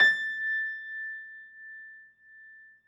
53h-pno21-A4.wav